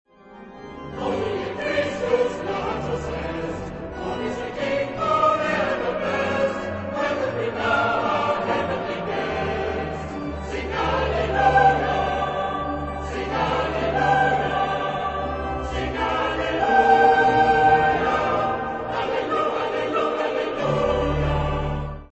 Zeitepoche: 20. Jh.
Chorgattung: SATB  (4 gemischter Chor Stimmen )
Instrumente: Orgel (1)